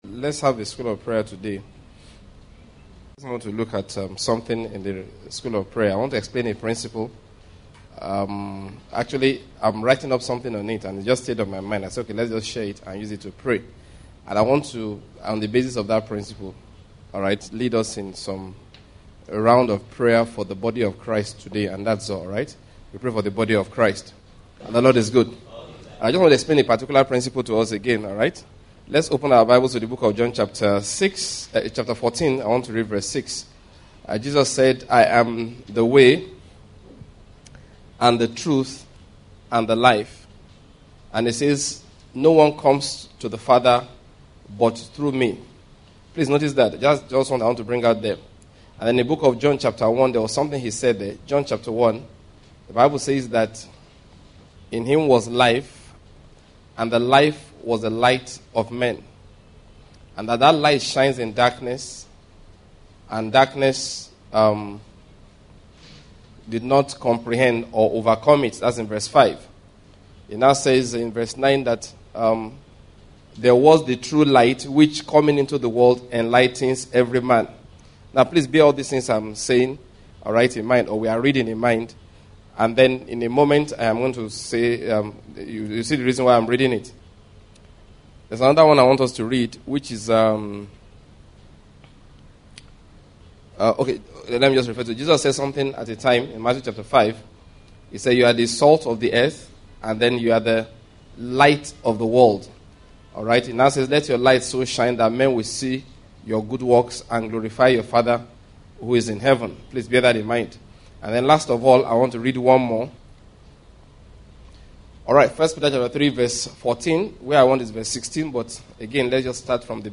LIFTING CHRIST'S BODY 2 messages Back to School of Prayers LIFTING CHRIST'S BODY 2 audio messages Download All LIFTING CHRIST'S BODY: This series is a set of prayer teachings and prayer times for the body of Christ. It starts with a very important teaching on how prayer works.